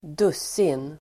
Uttal: [²d'us:in]